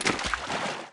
sounds / material / human / step / t_water3.ogg
t_water3.ogg